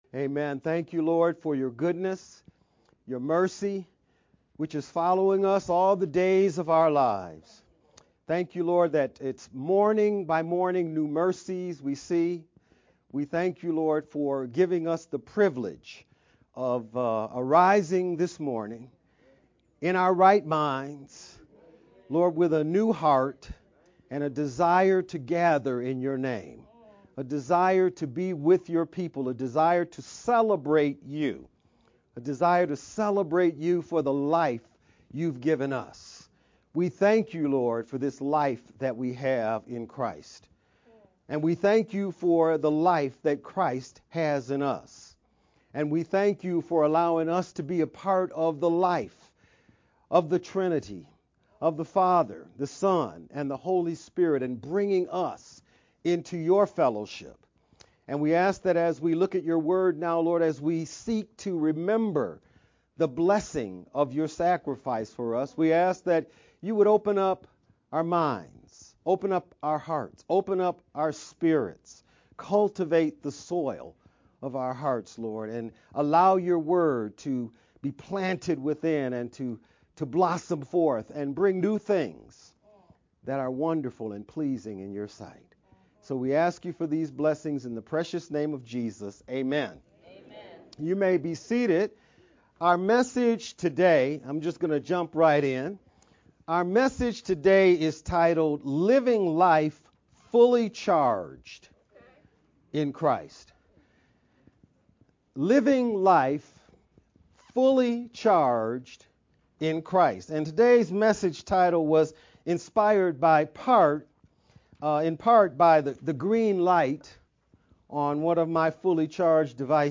VBCC-Service-2-16-sermon-only-edited_Converted-CD.mp3